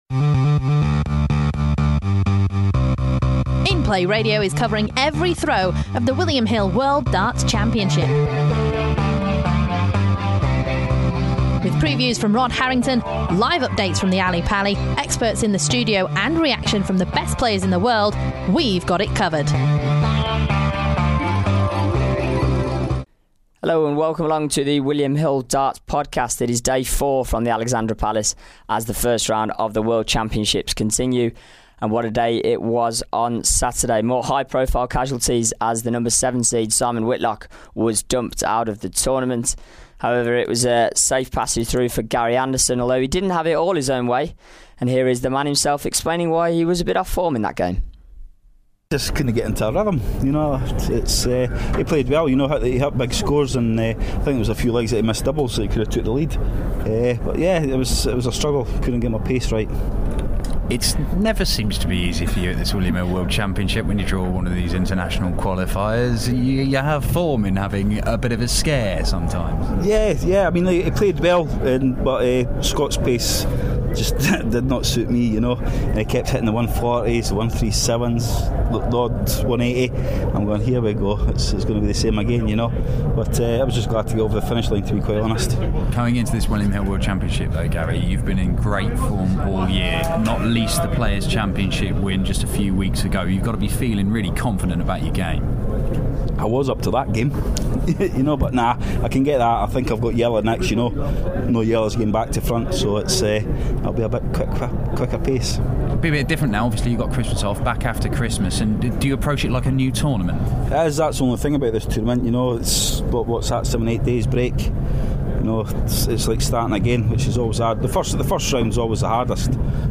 Check out the latest interviews and tips from Alexandra Palace and the William Hill World Darts Championship.
We'll hear from players and tipsters every day there's darting action.